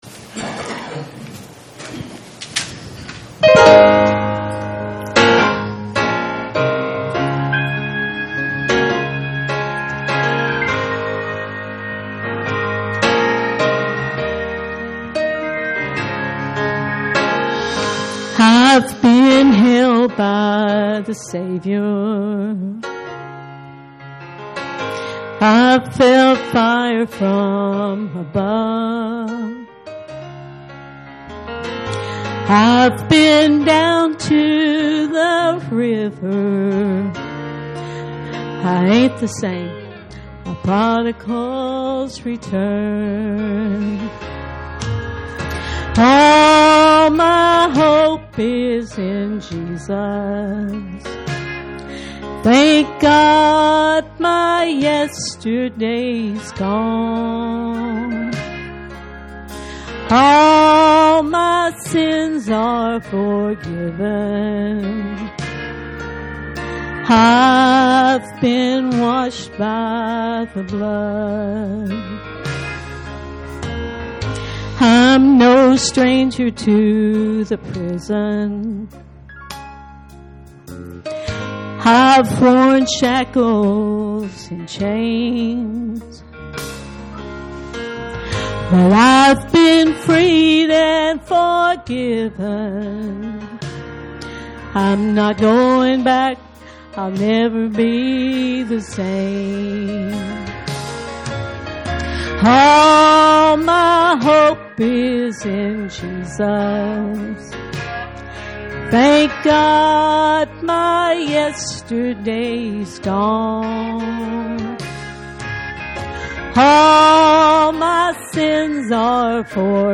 Special Music - Calvary Baptist Church